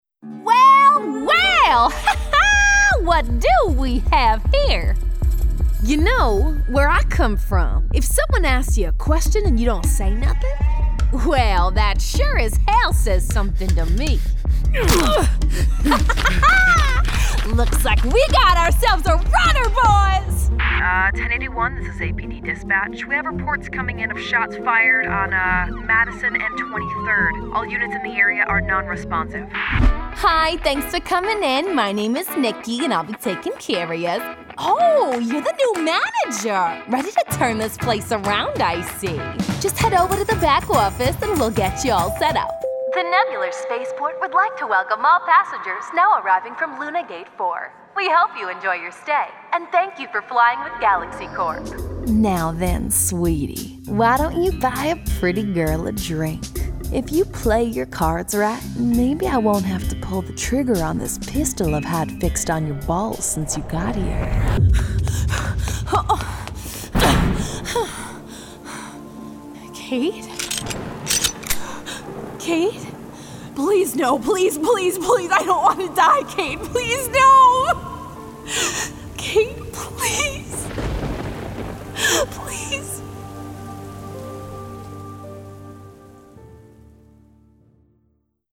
Warm, Friendly, Conversational.
Gaming